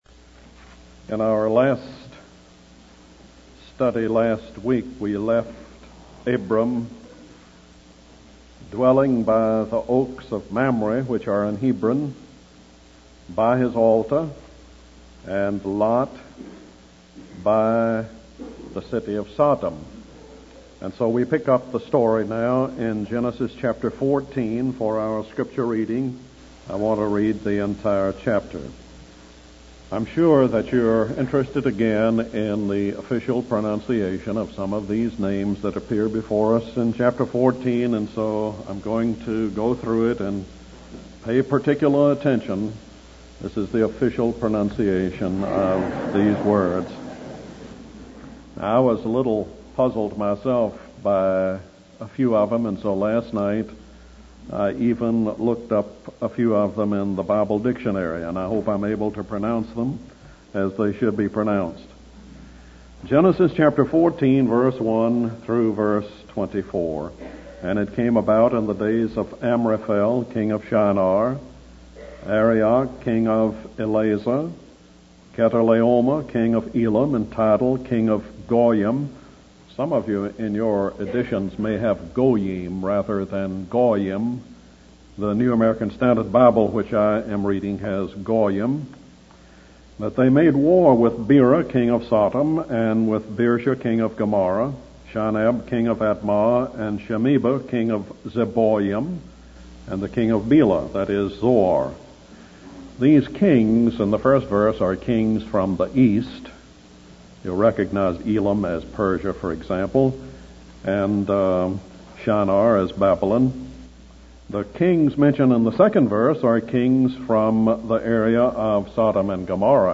In this sermon, the preacher focuses on the story of Abram in Genesis chapter 14. He describes Abram as a man who is both deeply connected to God and capable of handling worldly affairs, even in military matters. The sermon discusses a small war in which five rebel cities defy their masters and face swift punishment.